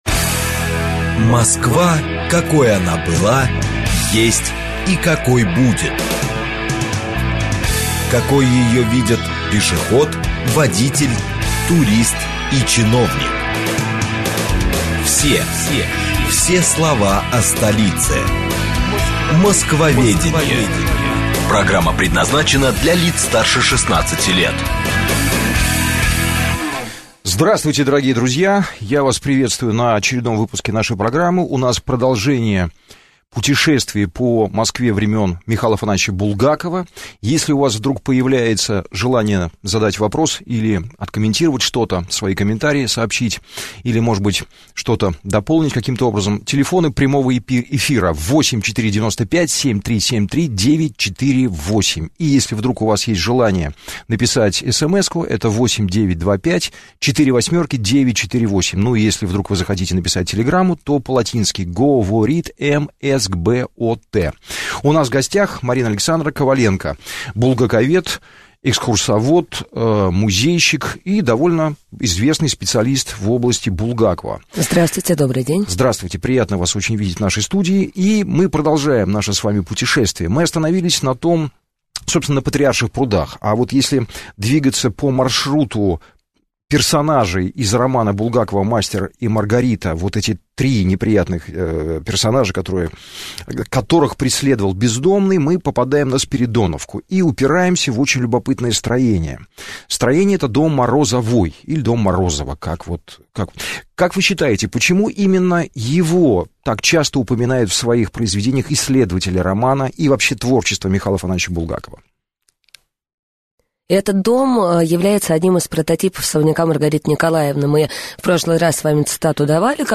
Аудиокнига Булгаковская Москва. Часть 2 | Библиотека аудиокниг